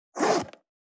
428,ジッパー,チャックの音,
ジッパー